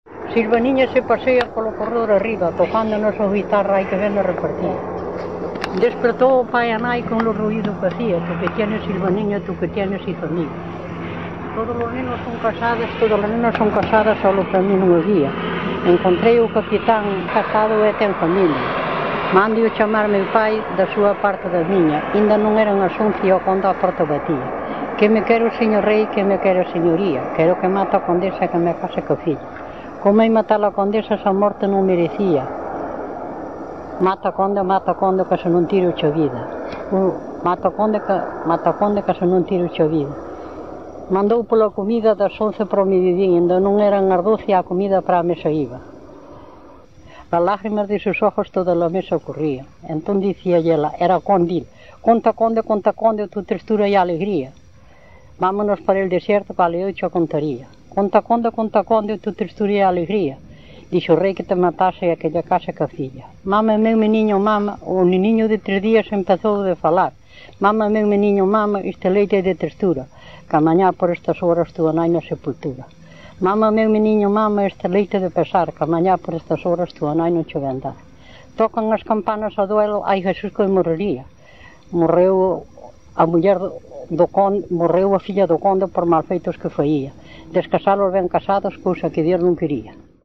Este tema � un romance xograresco do que se co�ece unha versi�n moi longa de 1454, por�n os textos modernos parecen derivar dunha versi�n abreviada, que non foi impresa polos editores antigos.
Tradici�n oral. Bande (1998) Conde Alarcos 1 minuto e 30 segundos conde_alarcos.mp3 (.mp3 1.4 Mb) Subcampos: 1976-2002 , Poes�a popular , Materiais �ditos , Historia literaria , Poes�a tradicional , Artigos ou cap�tulos de libro